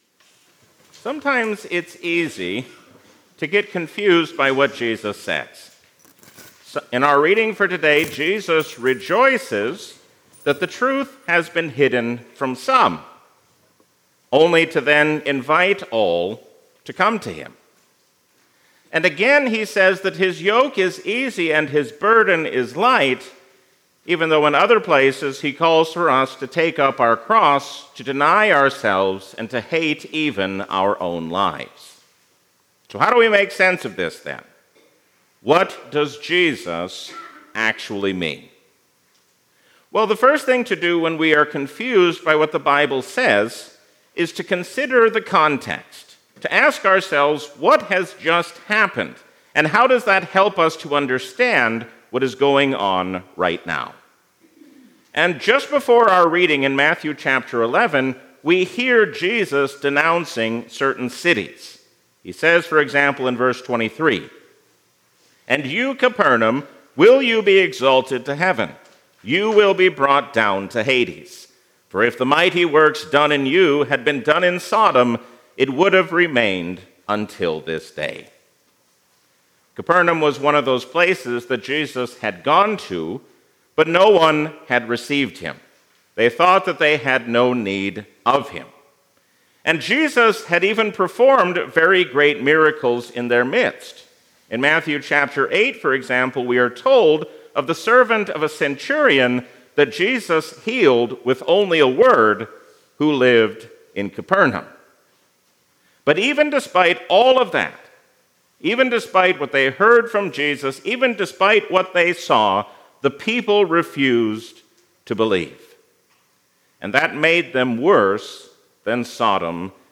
Sermon
A sermon from the season "Advent 2025."